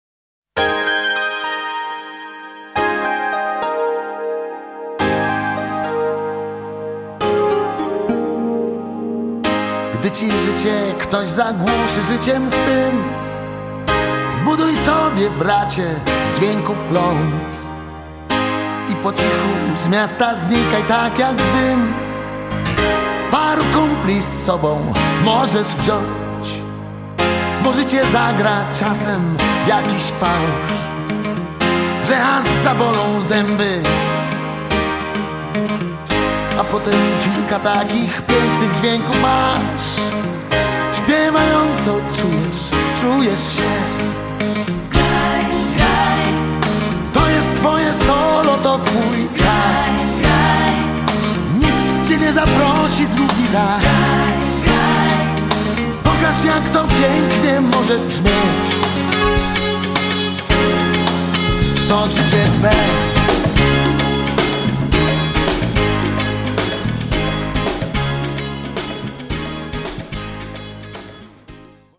BAND DUET TEXT
VOC GUITAR KEYB BASS DRUMS   TEKST